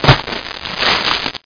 splash.mp3